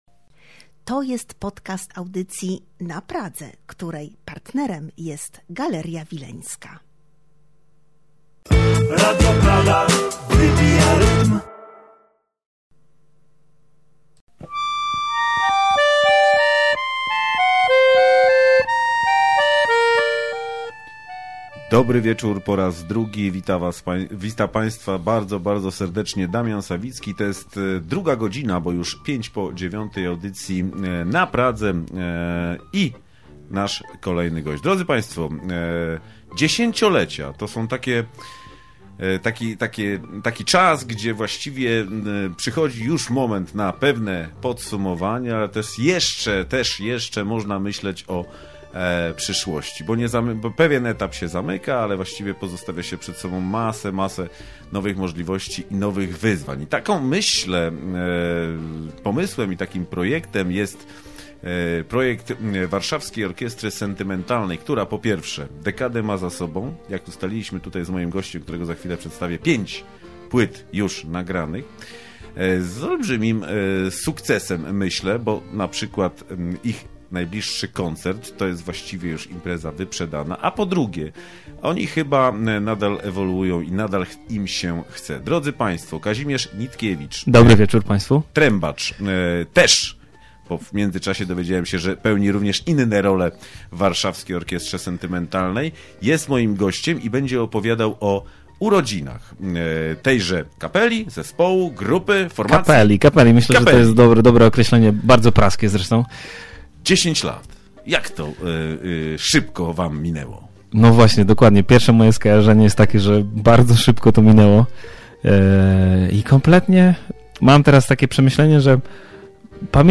To muzyka do słuchania, tańca, zabawy.